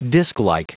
Prononciation du mot disklike en anglais (fichier audio)
Prononciation du mot : disklike